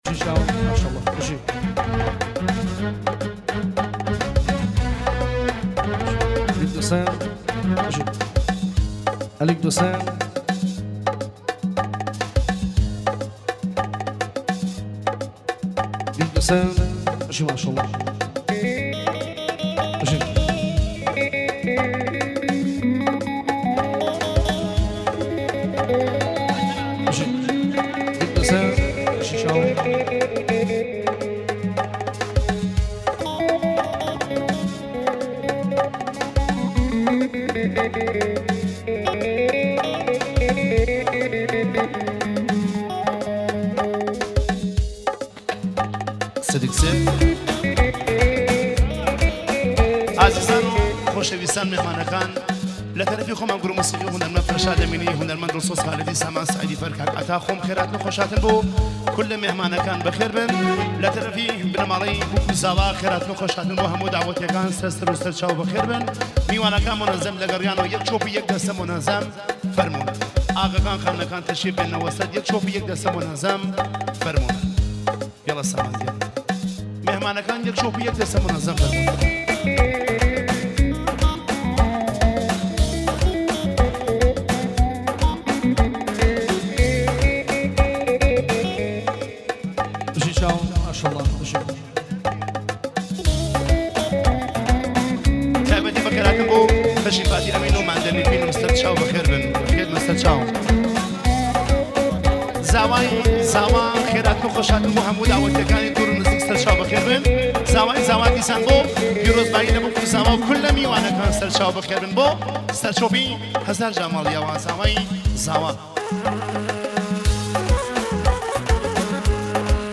کردی شاد